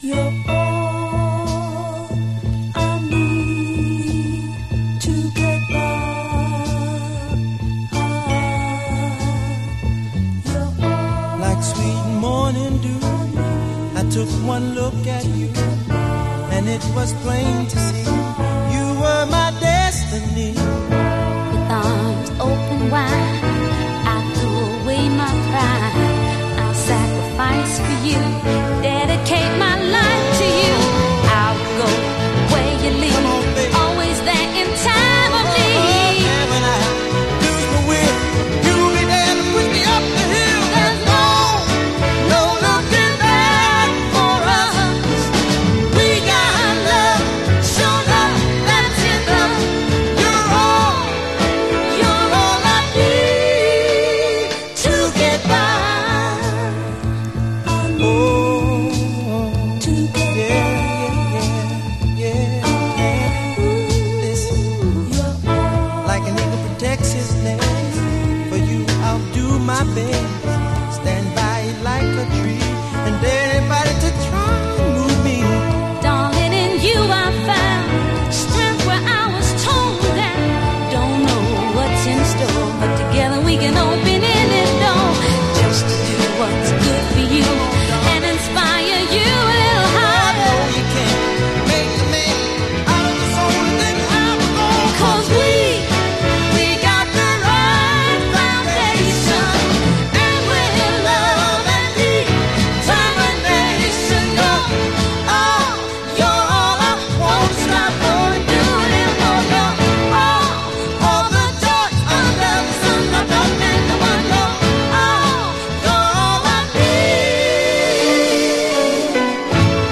Genre: Northern Soul, Motown Style